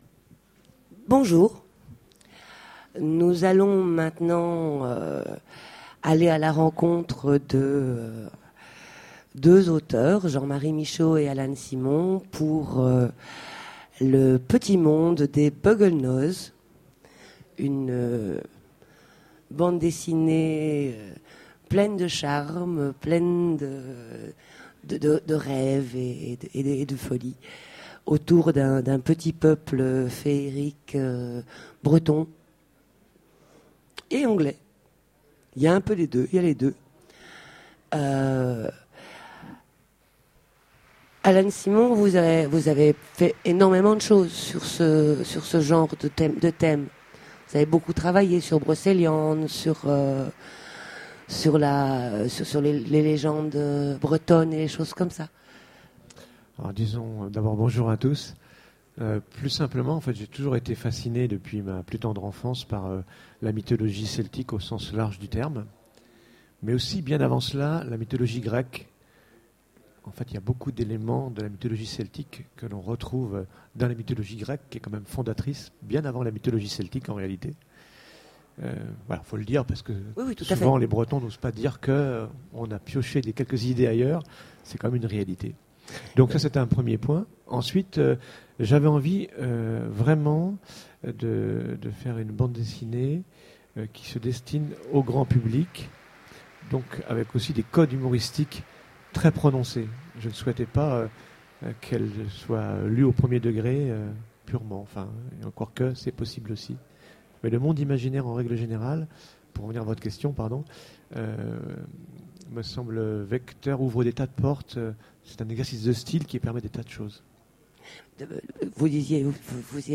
Utopiales 13 : Conférence Le monde des Buggels Noz
Conférence